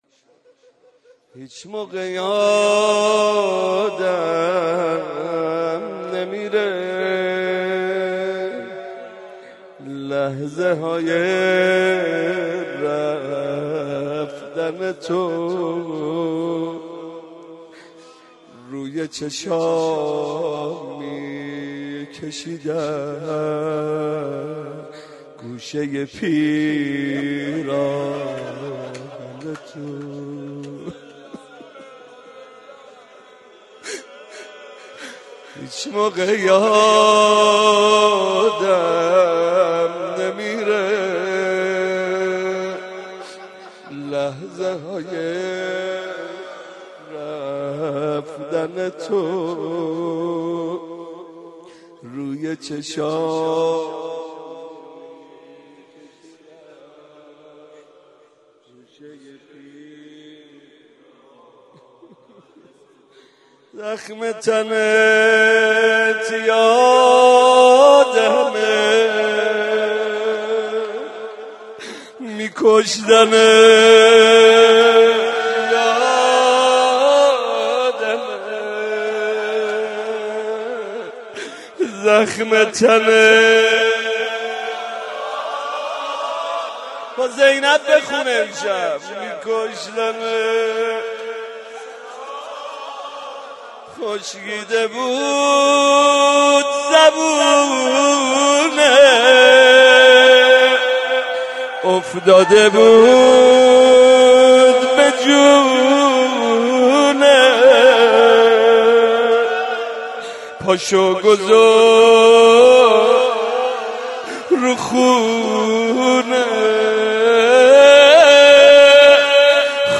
روضه
03.rozeh.mp3